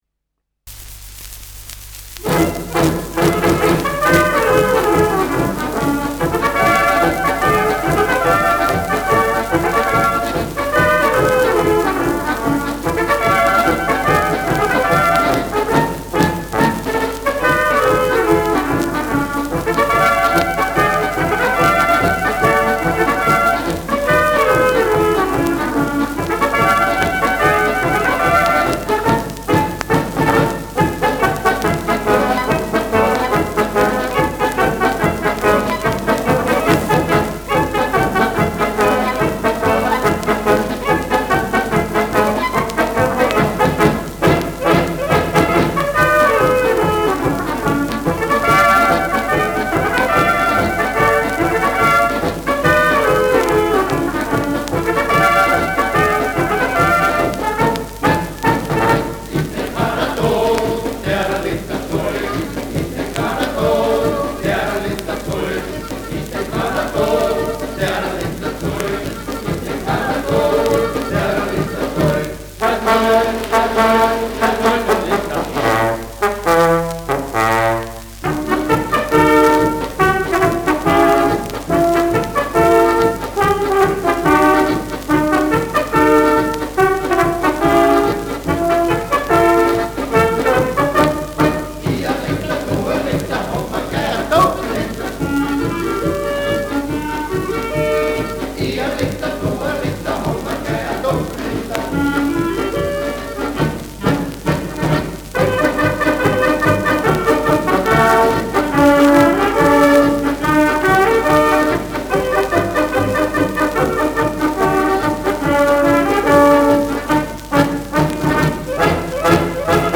Bier-Marsch : Volksweise : mit Gesang
Schellackplatte
leichtes Rauschen : präsentes Knistern